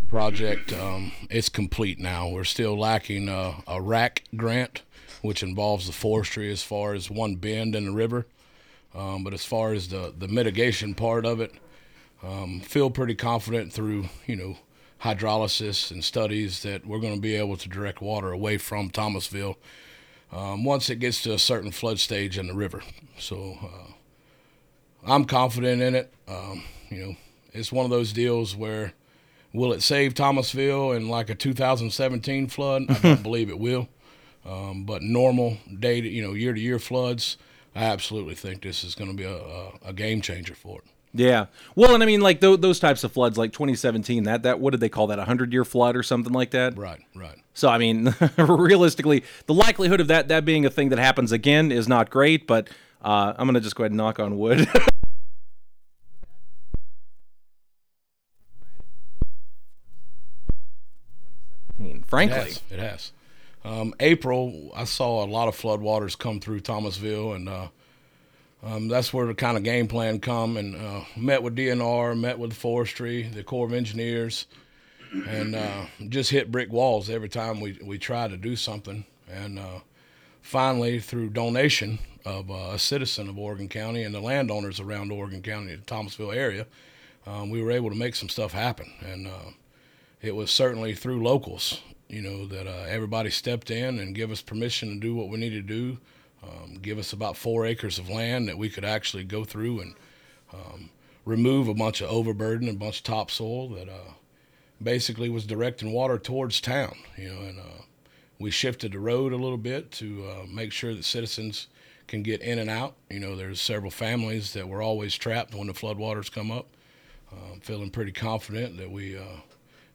Oregon County Commissioner Jake Parker in the studio of KUKU on February 2nd, 2026.
This morning on KUKU 100.3, we spoke with Oregon County Associate Commissioner Jake Parker about various topics taking place within the county in 2026 through different talk segments of the Request Hour.